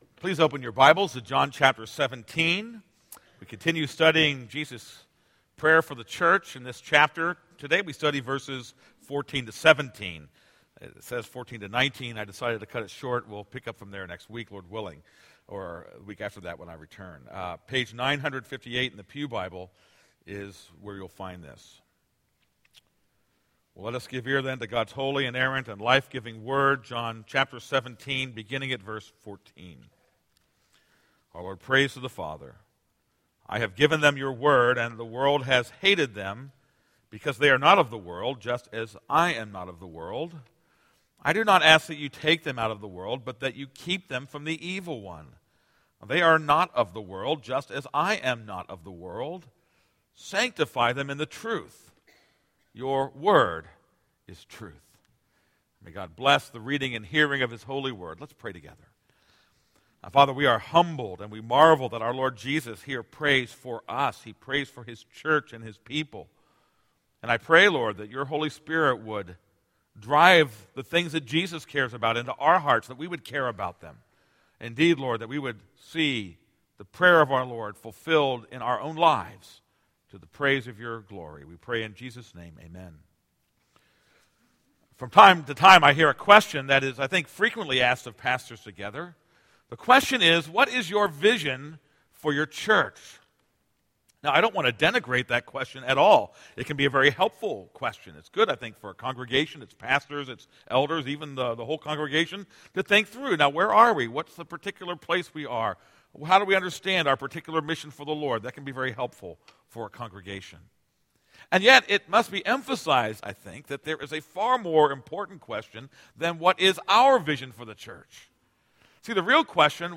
This is a sermon on John 17:14-19.